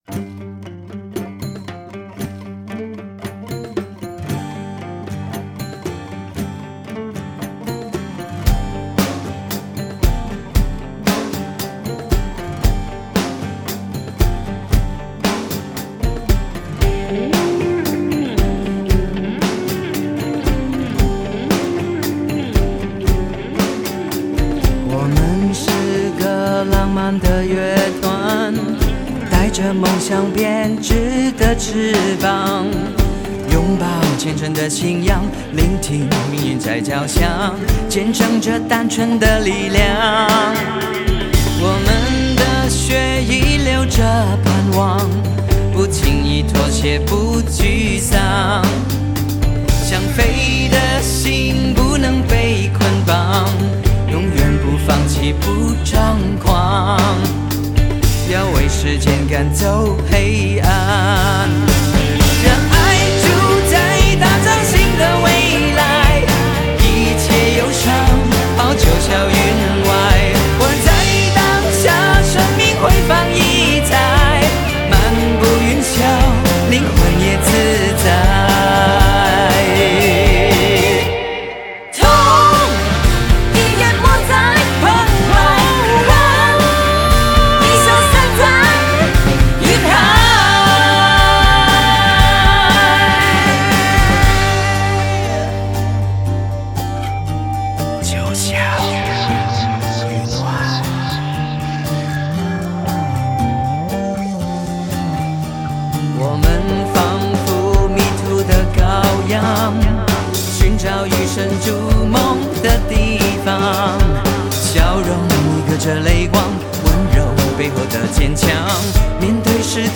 音乐风格: 流行
结合摇滚、民瑶、中国风、Balled、Gospel 于一身
低调的霸气、温和的神秘、温柔的摇滚